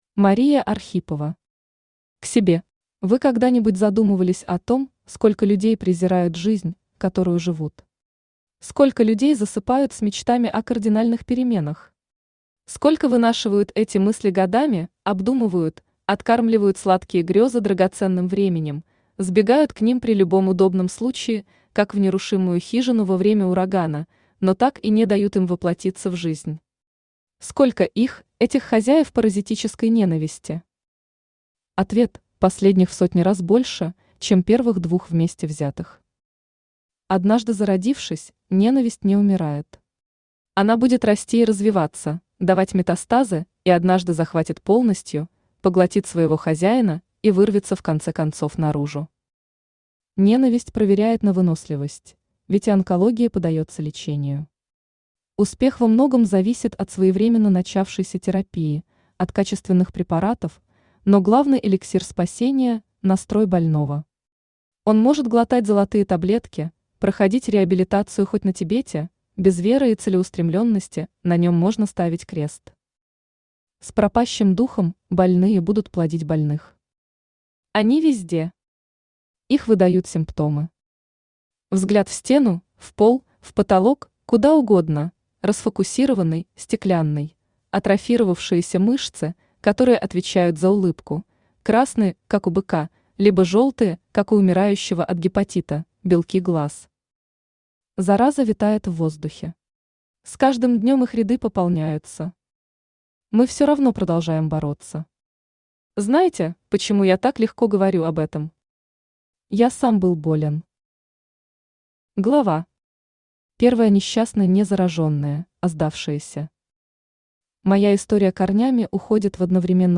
Аудиокнига К себе | Библиотека аудиокниг
Aудиокнига К себе Автор Мария Архипова Читает аудиокнигу Авточтец ЛитРес.